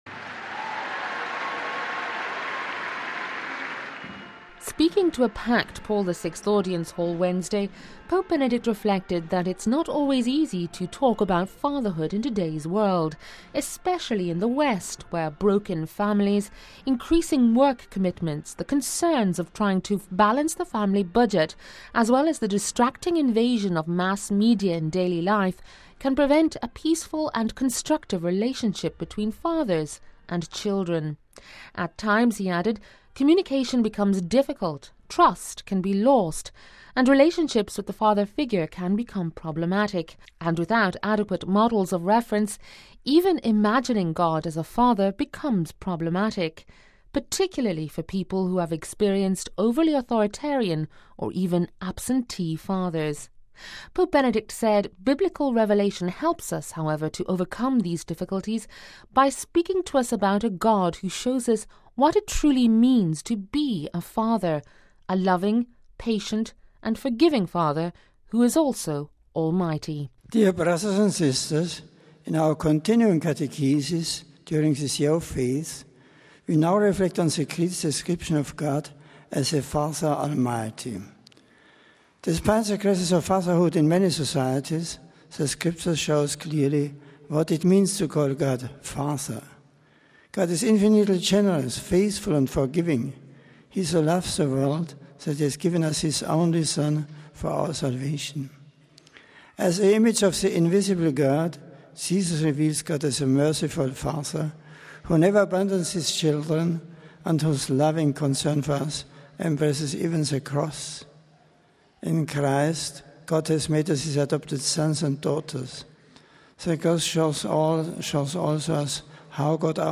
Speaking to a packed Paul VI audience hall, Pope Benedict reflected that it is not always easy today to talk about fatherhood.